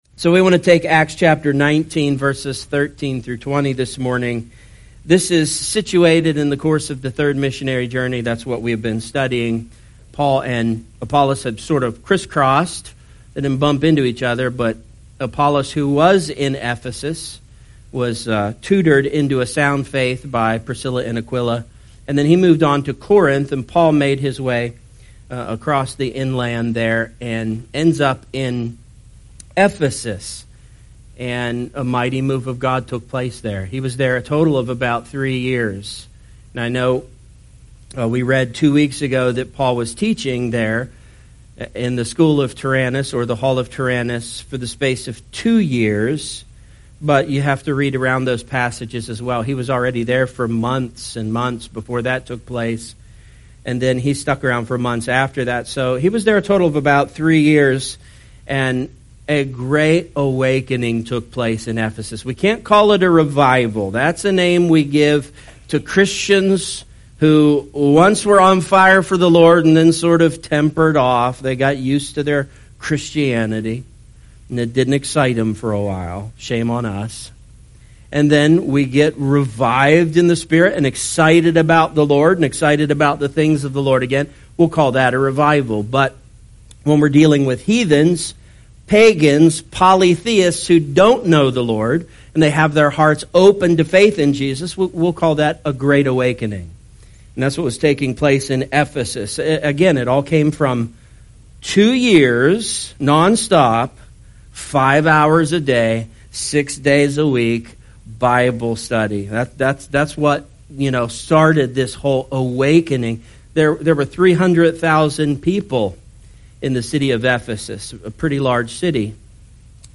A message from the topics "The Book of Acts."